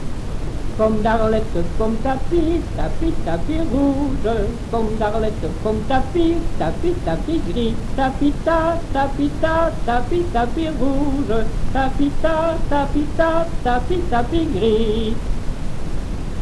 Genre : chant
Type : comptine, formulette
Lieu d'enregistrement : Verviers
Support : bande magnétique
Incipit : C C C C G C E G G G
Comptine.